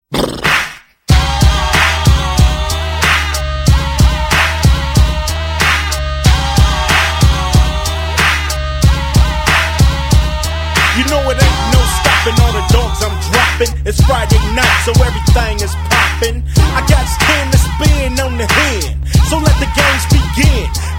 • Качество: 128, Stereo